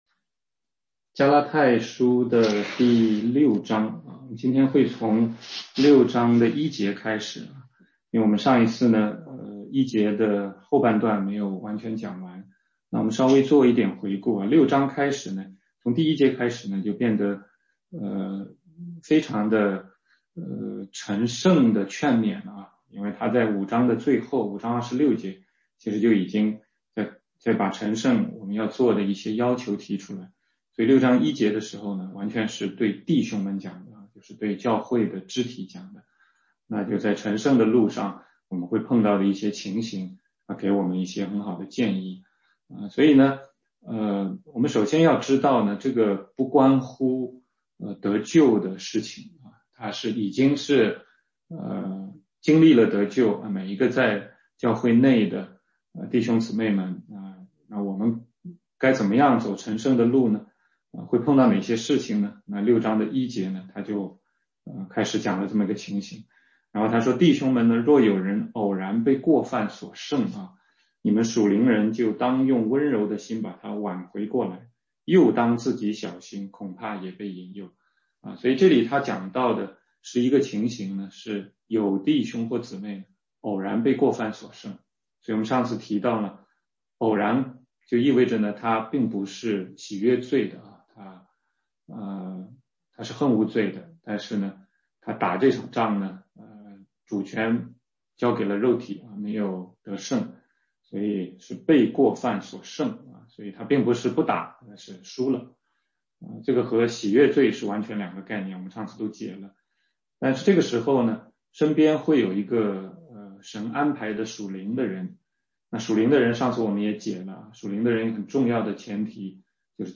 16街讲道录音 - 加拉太书6章1-8节：各人必担当自己的担子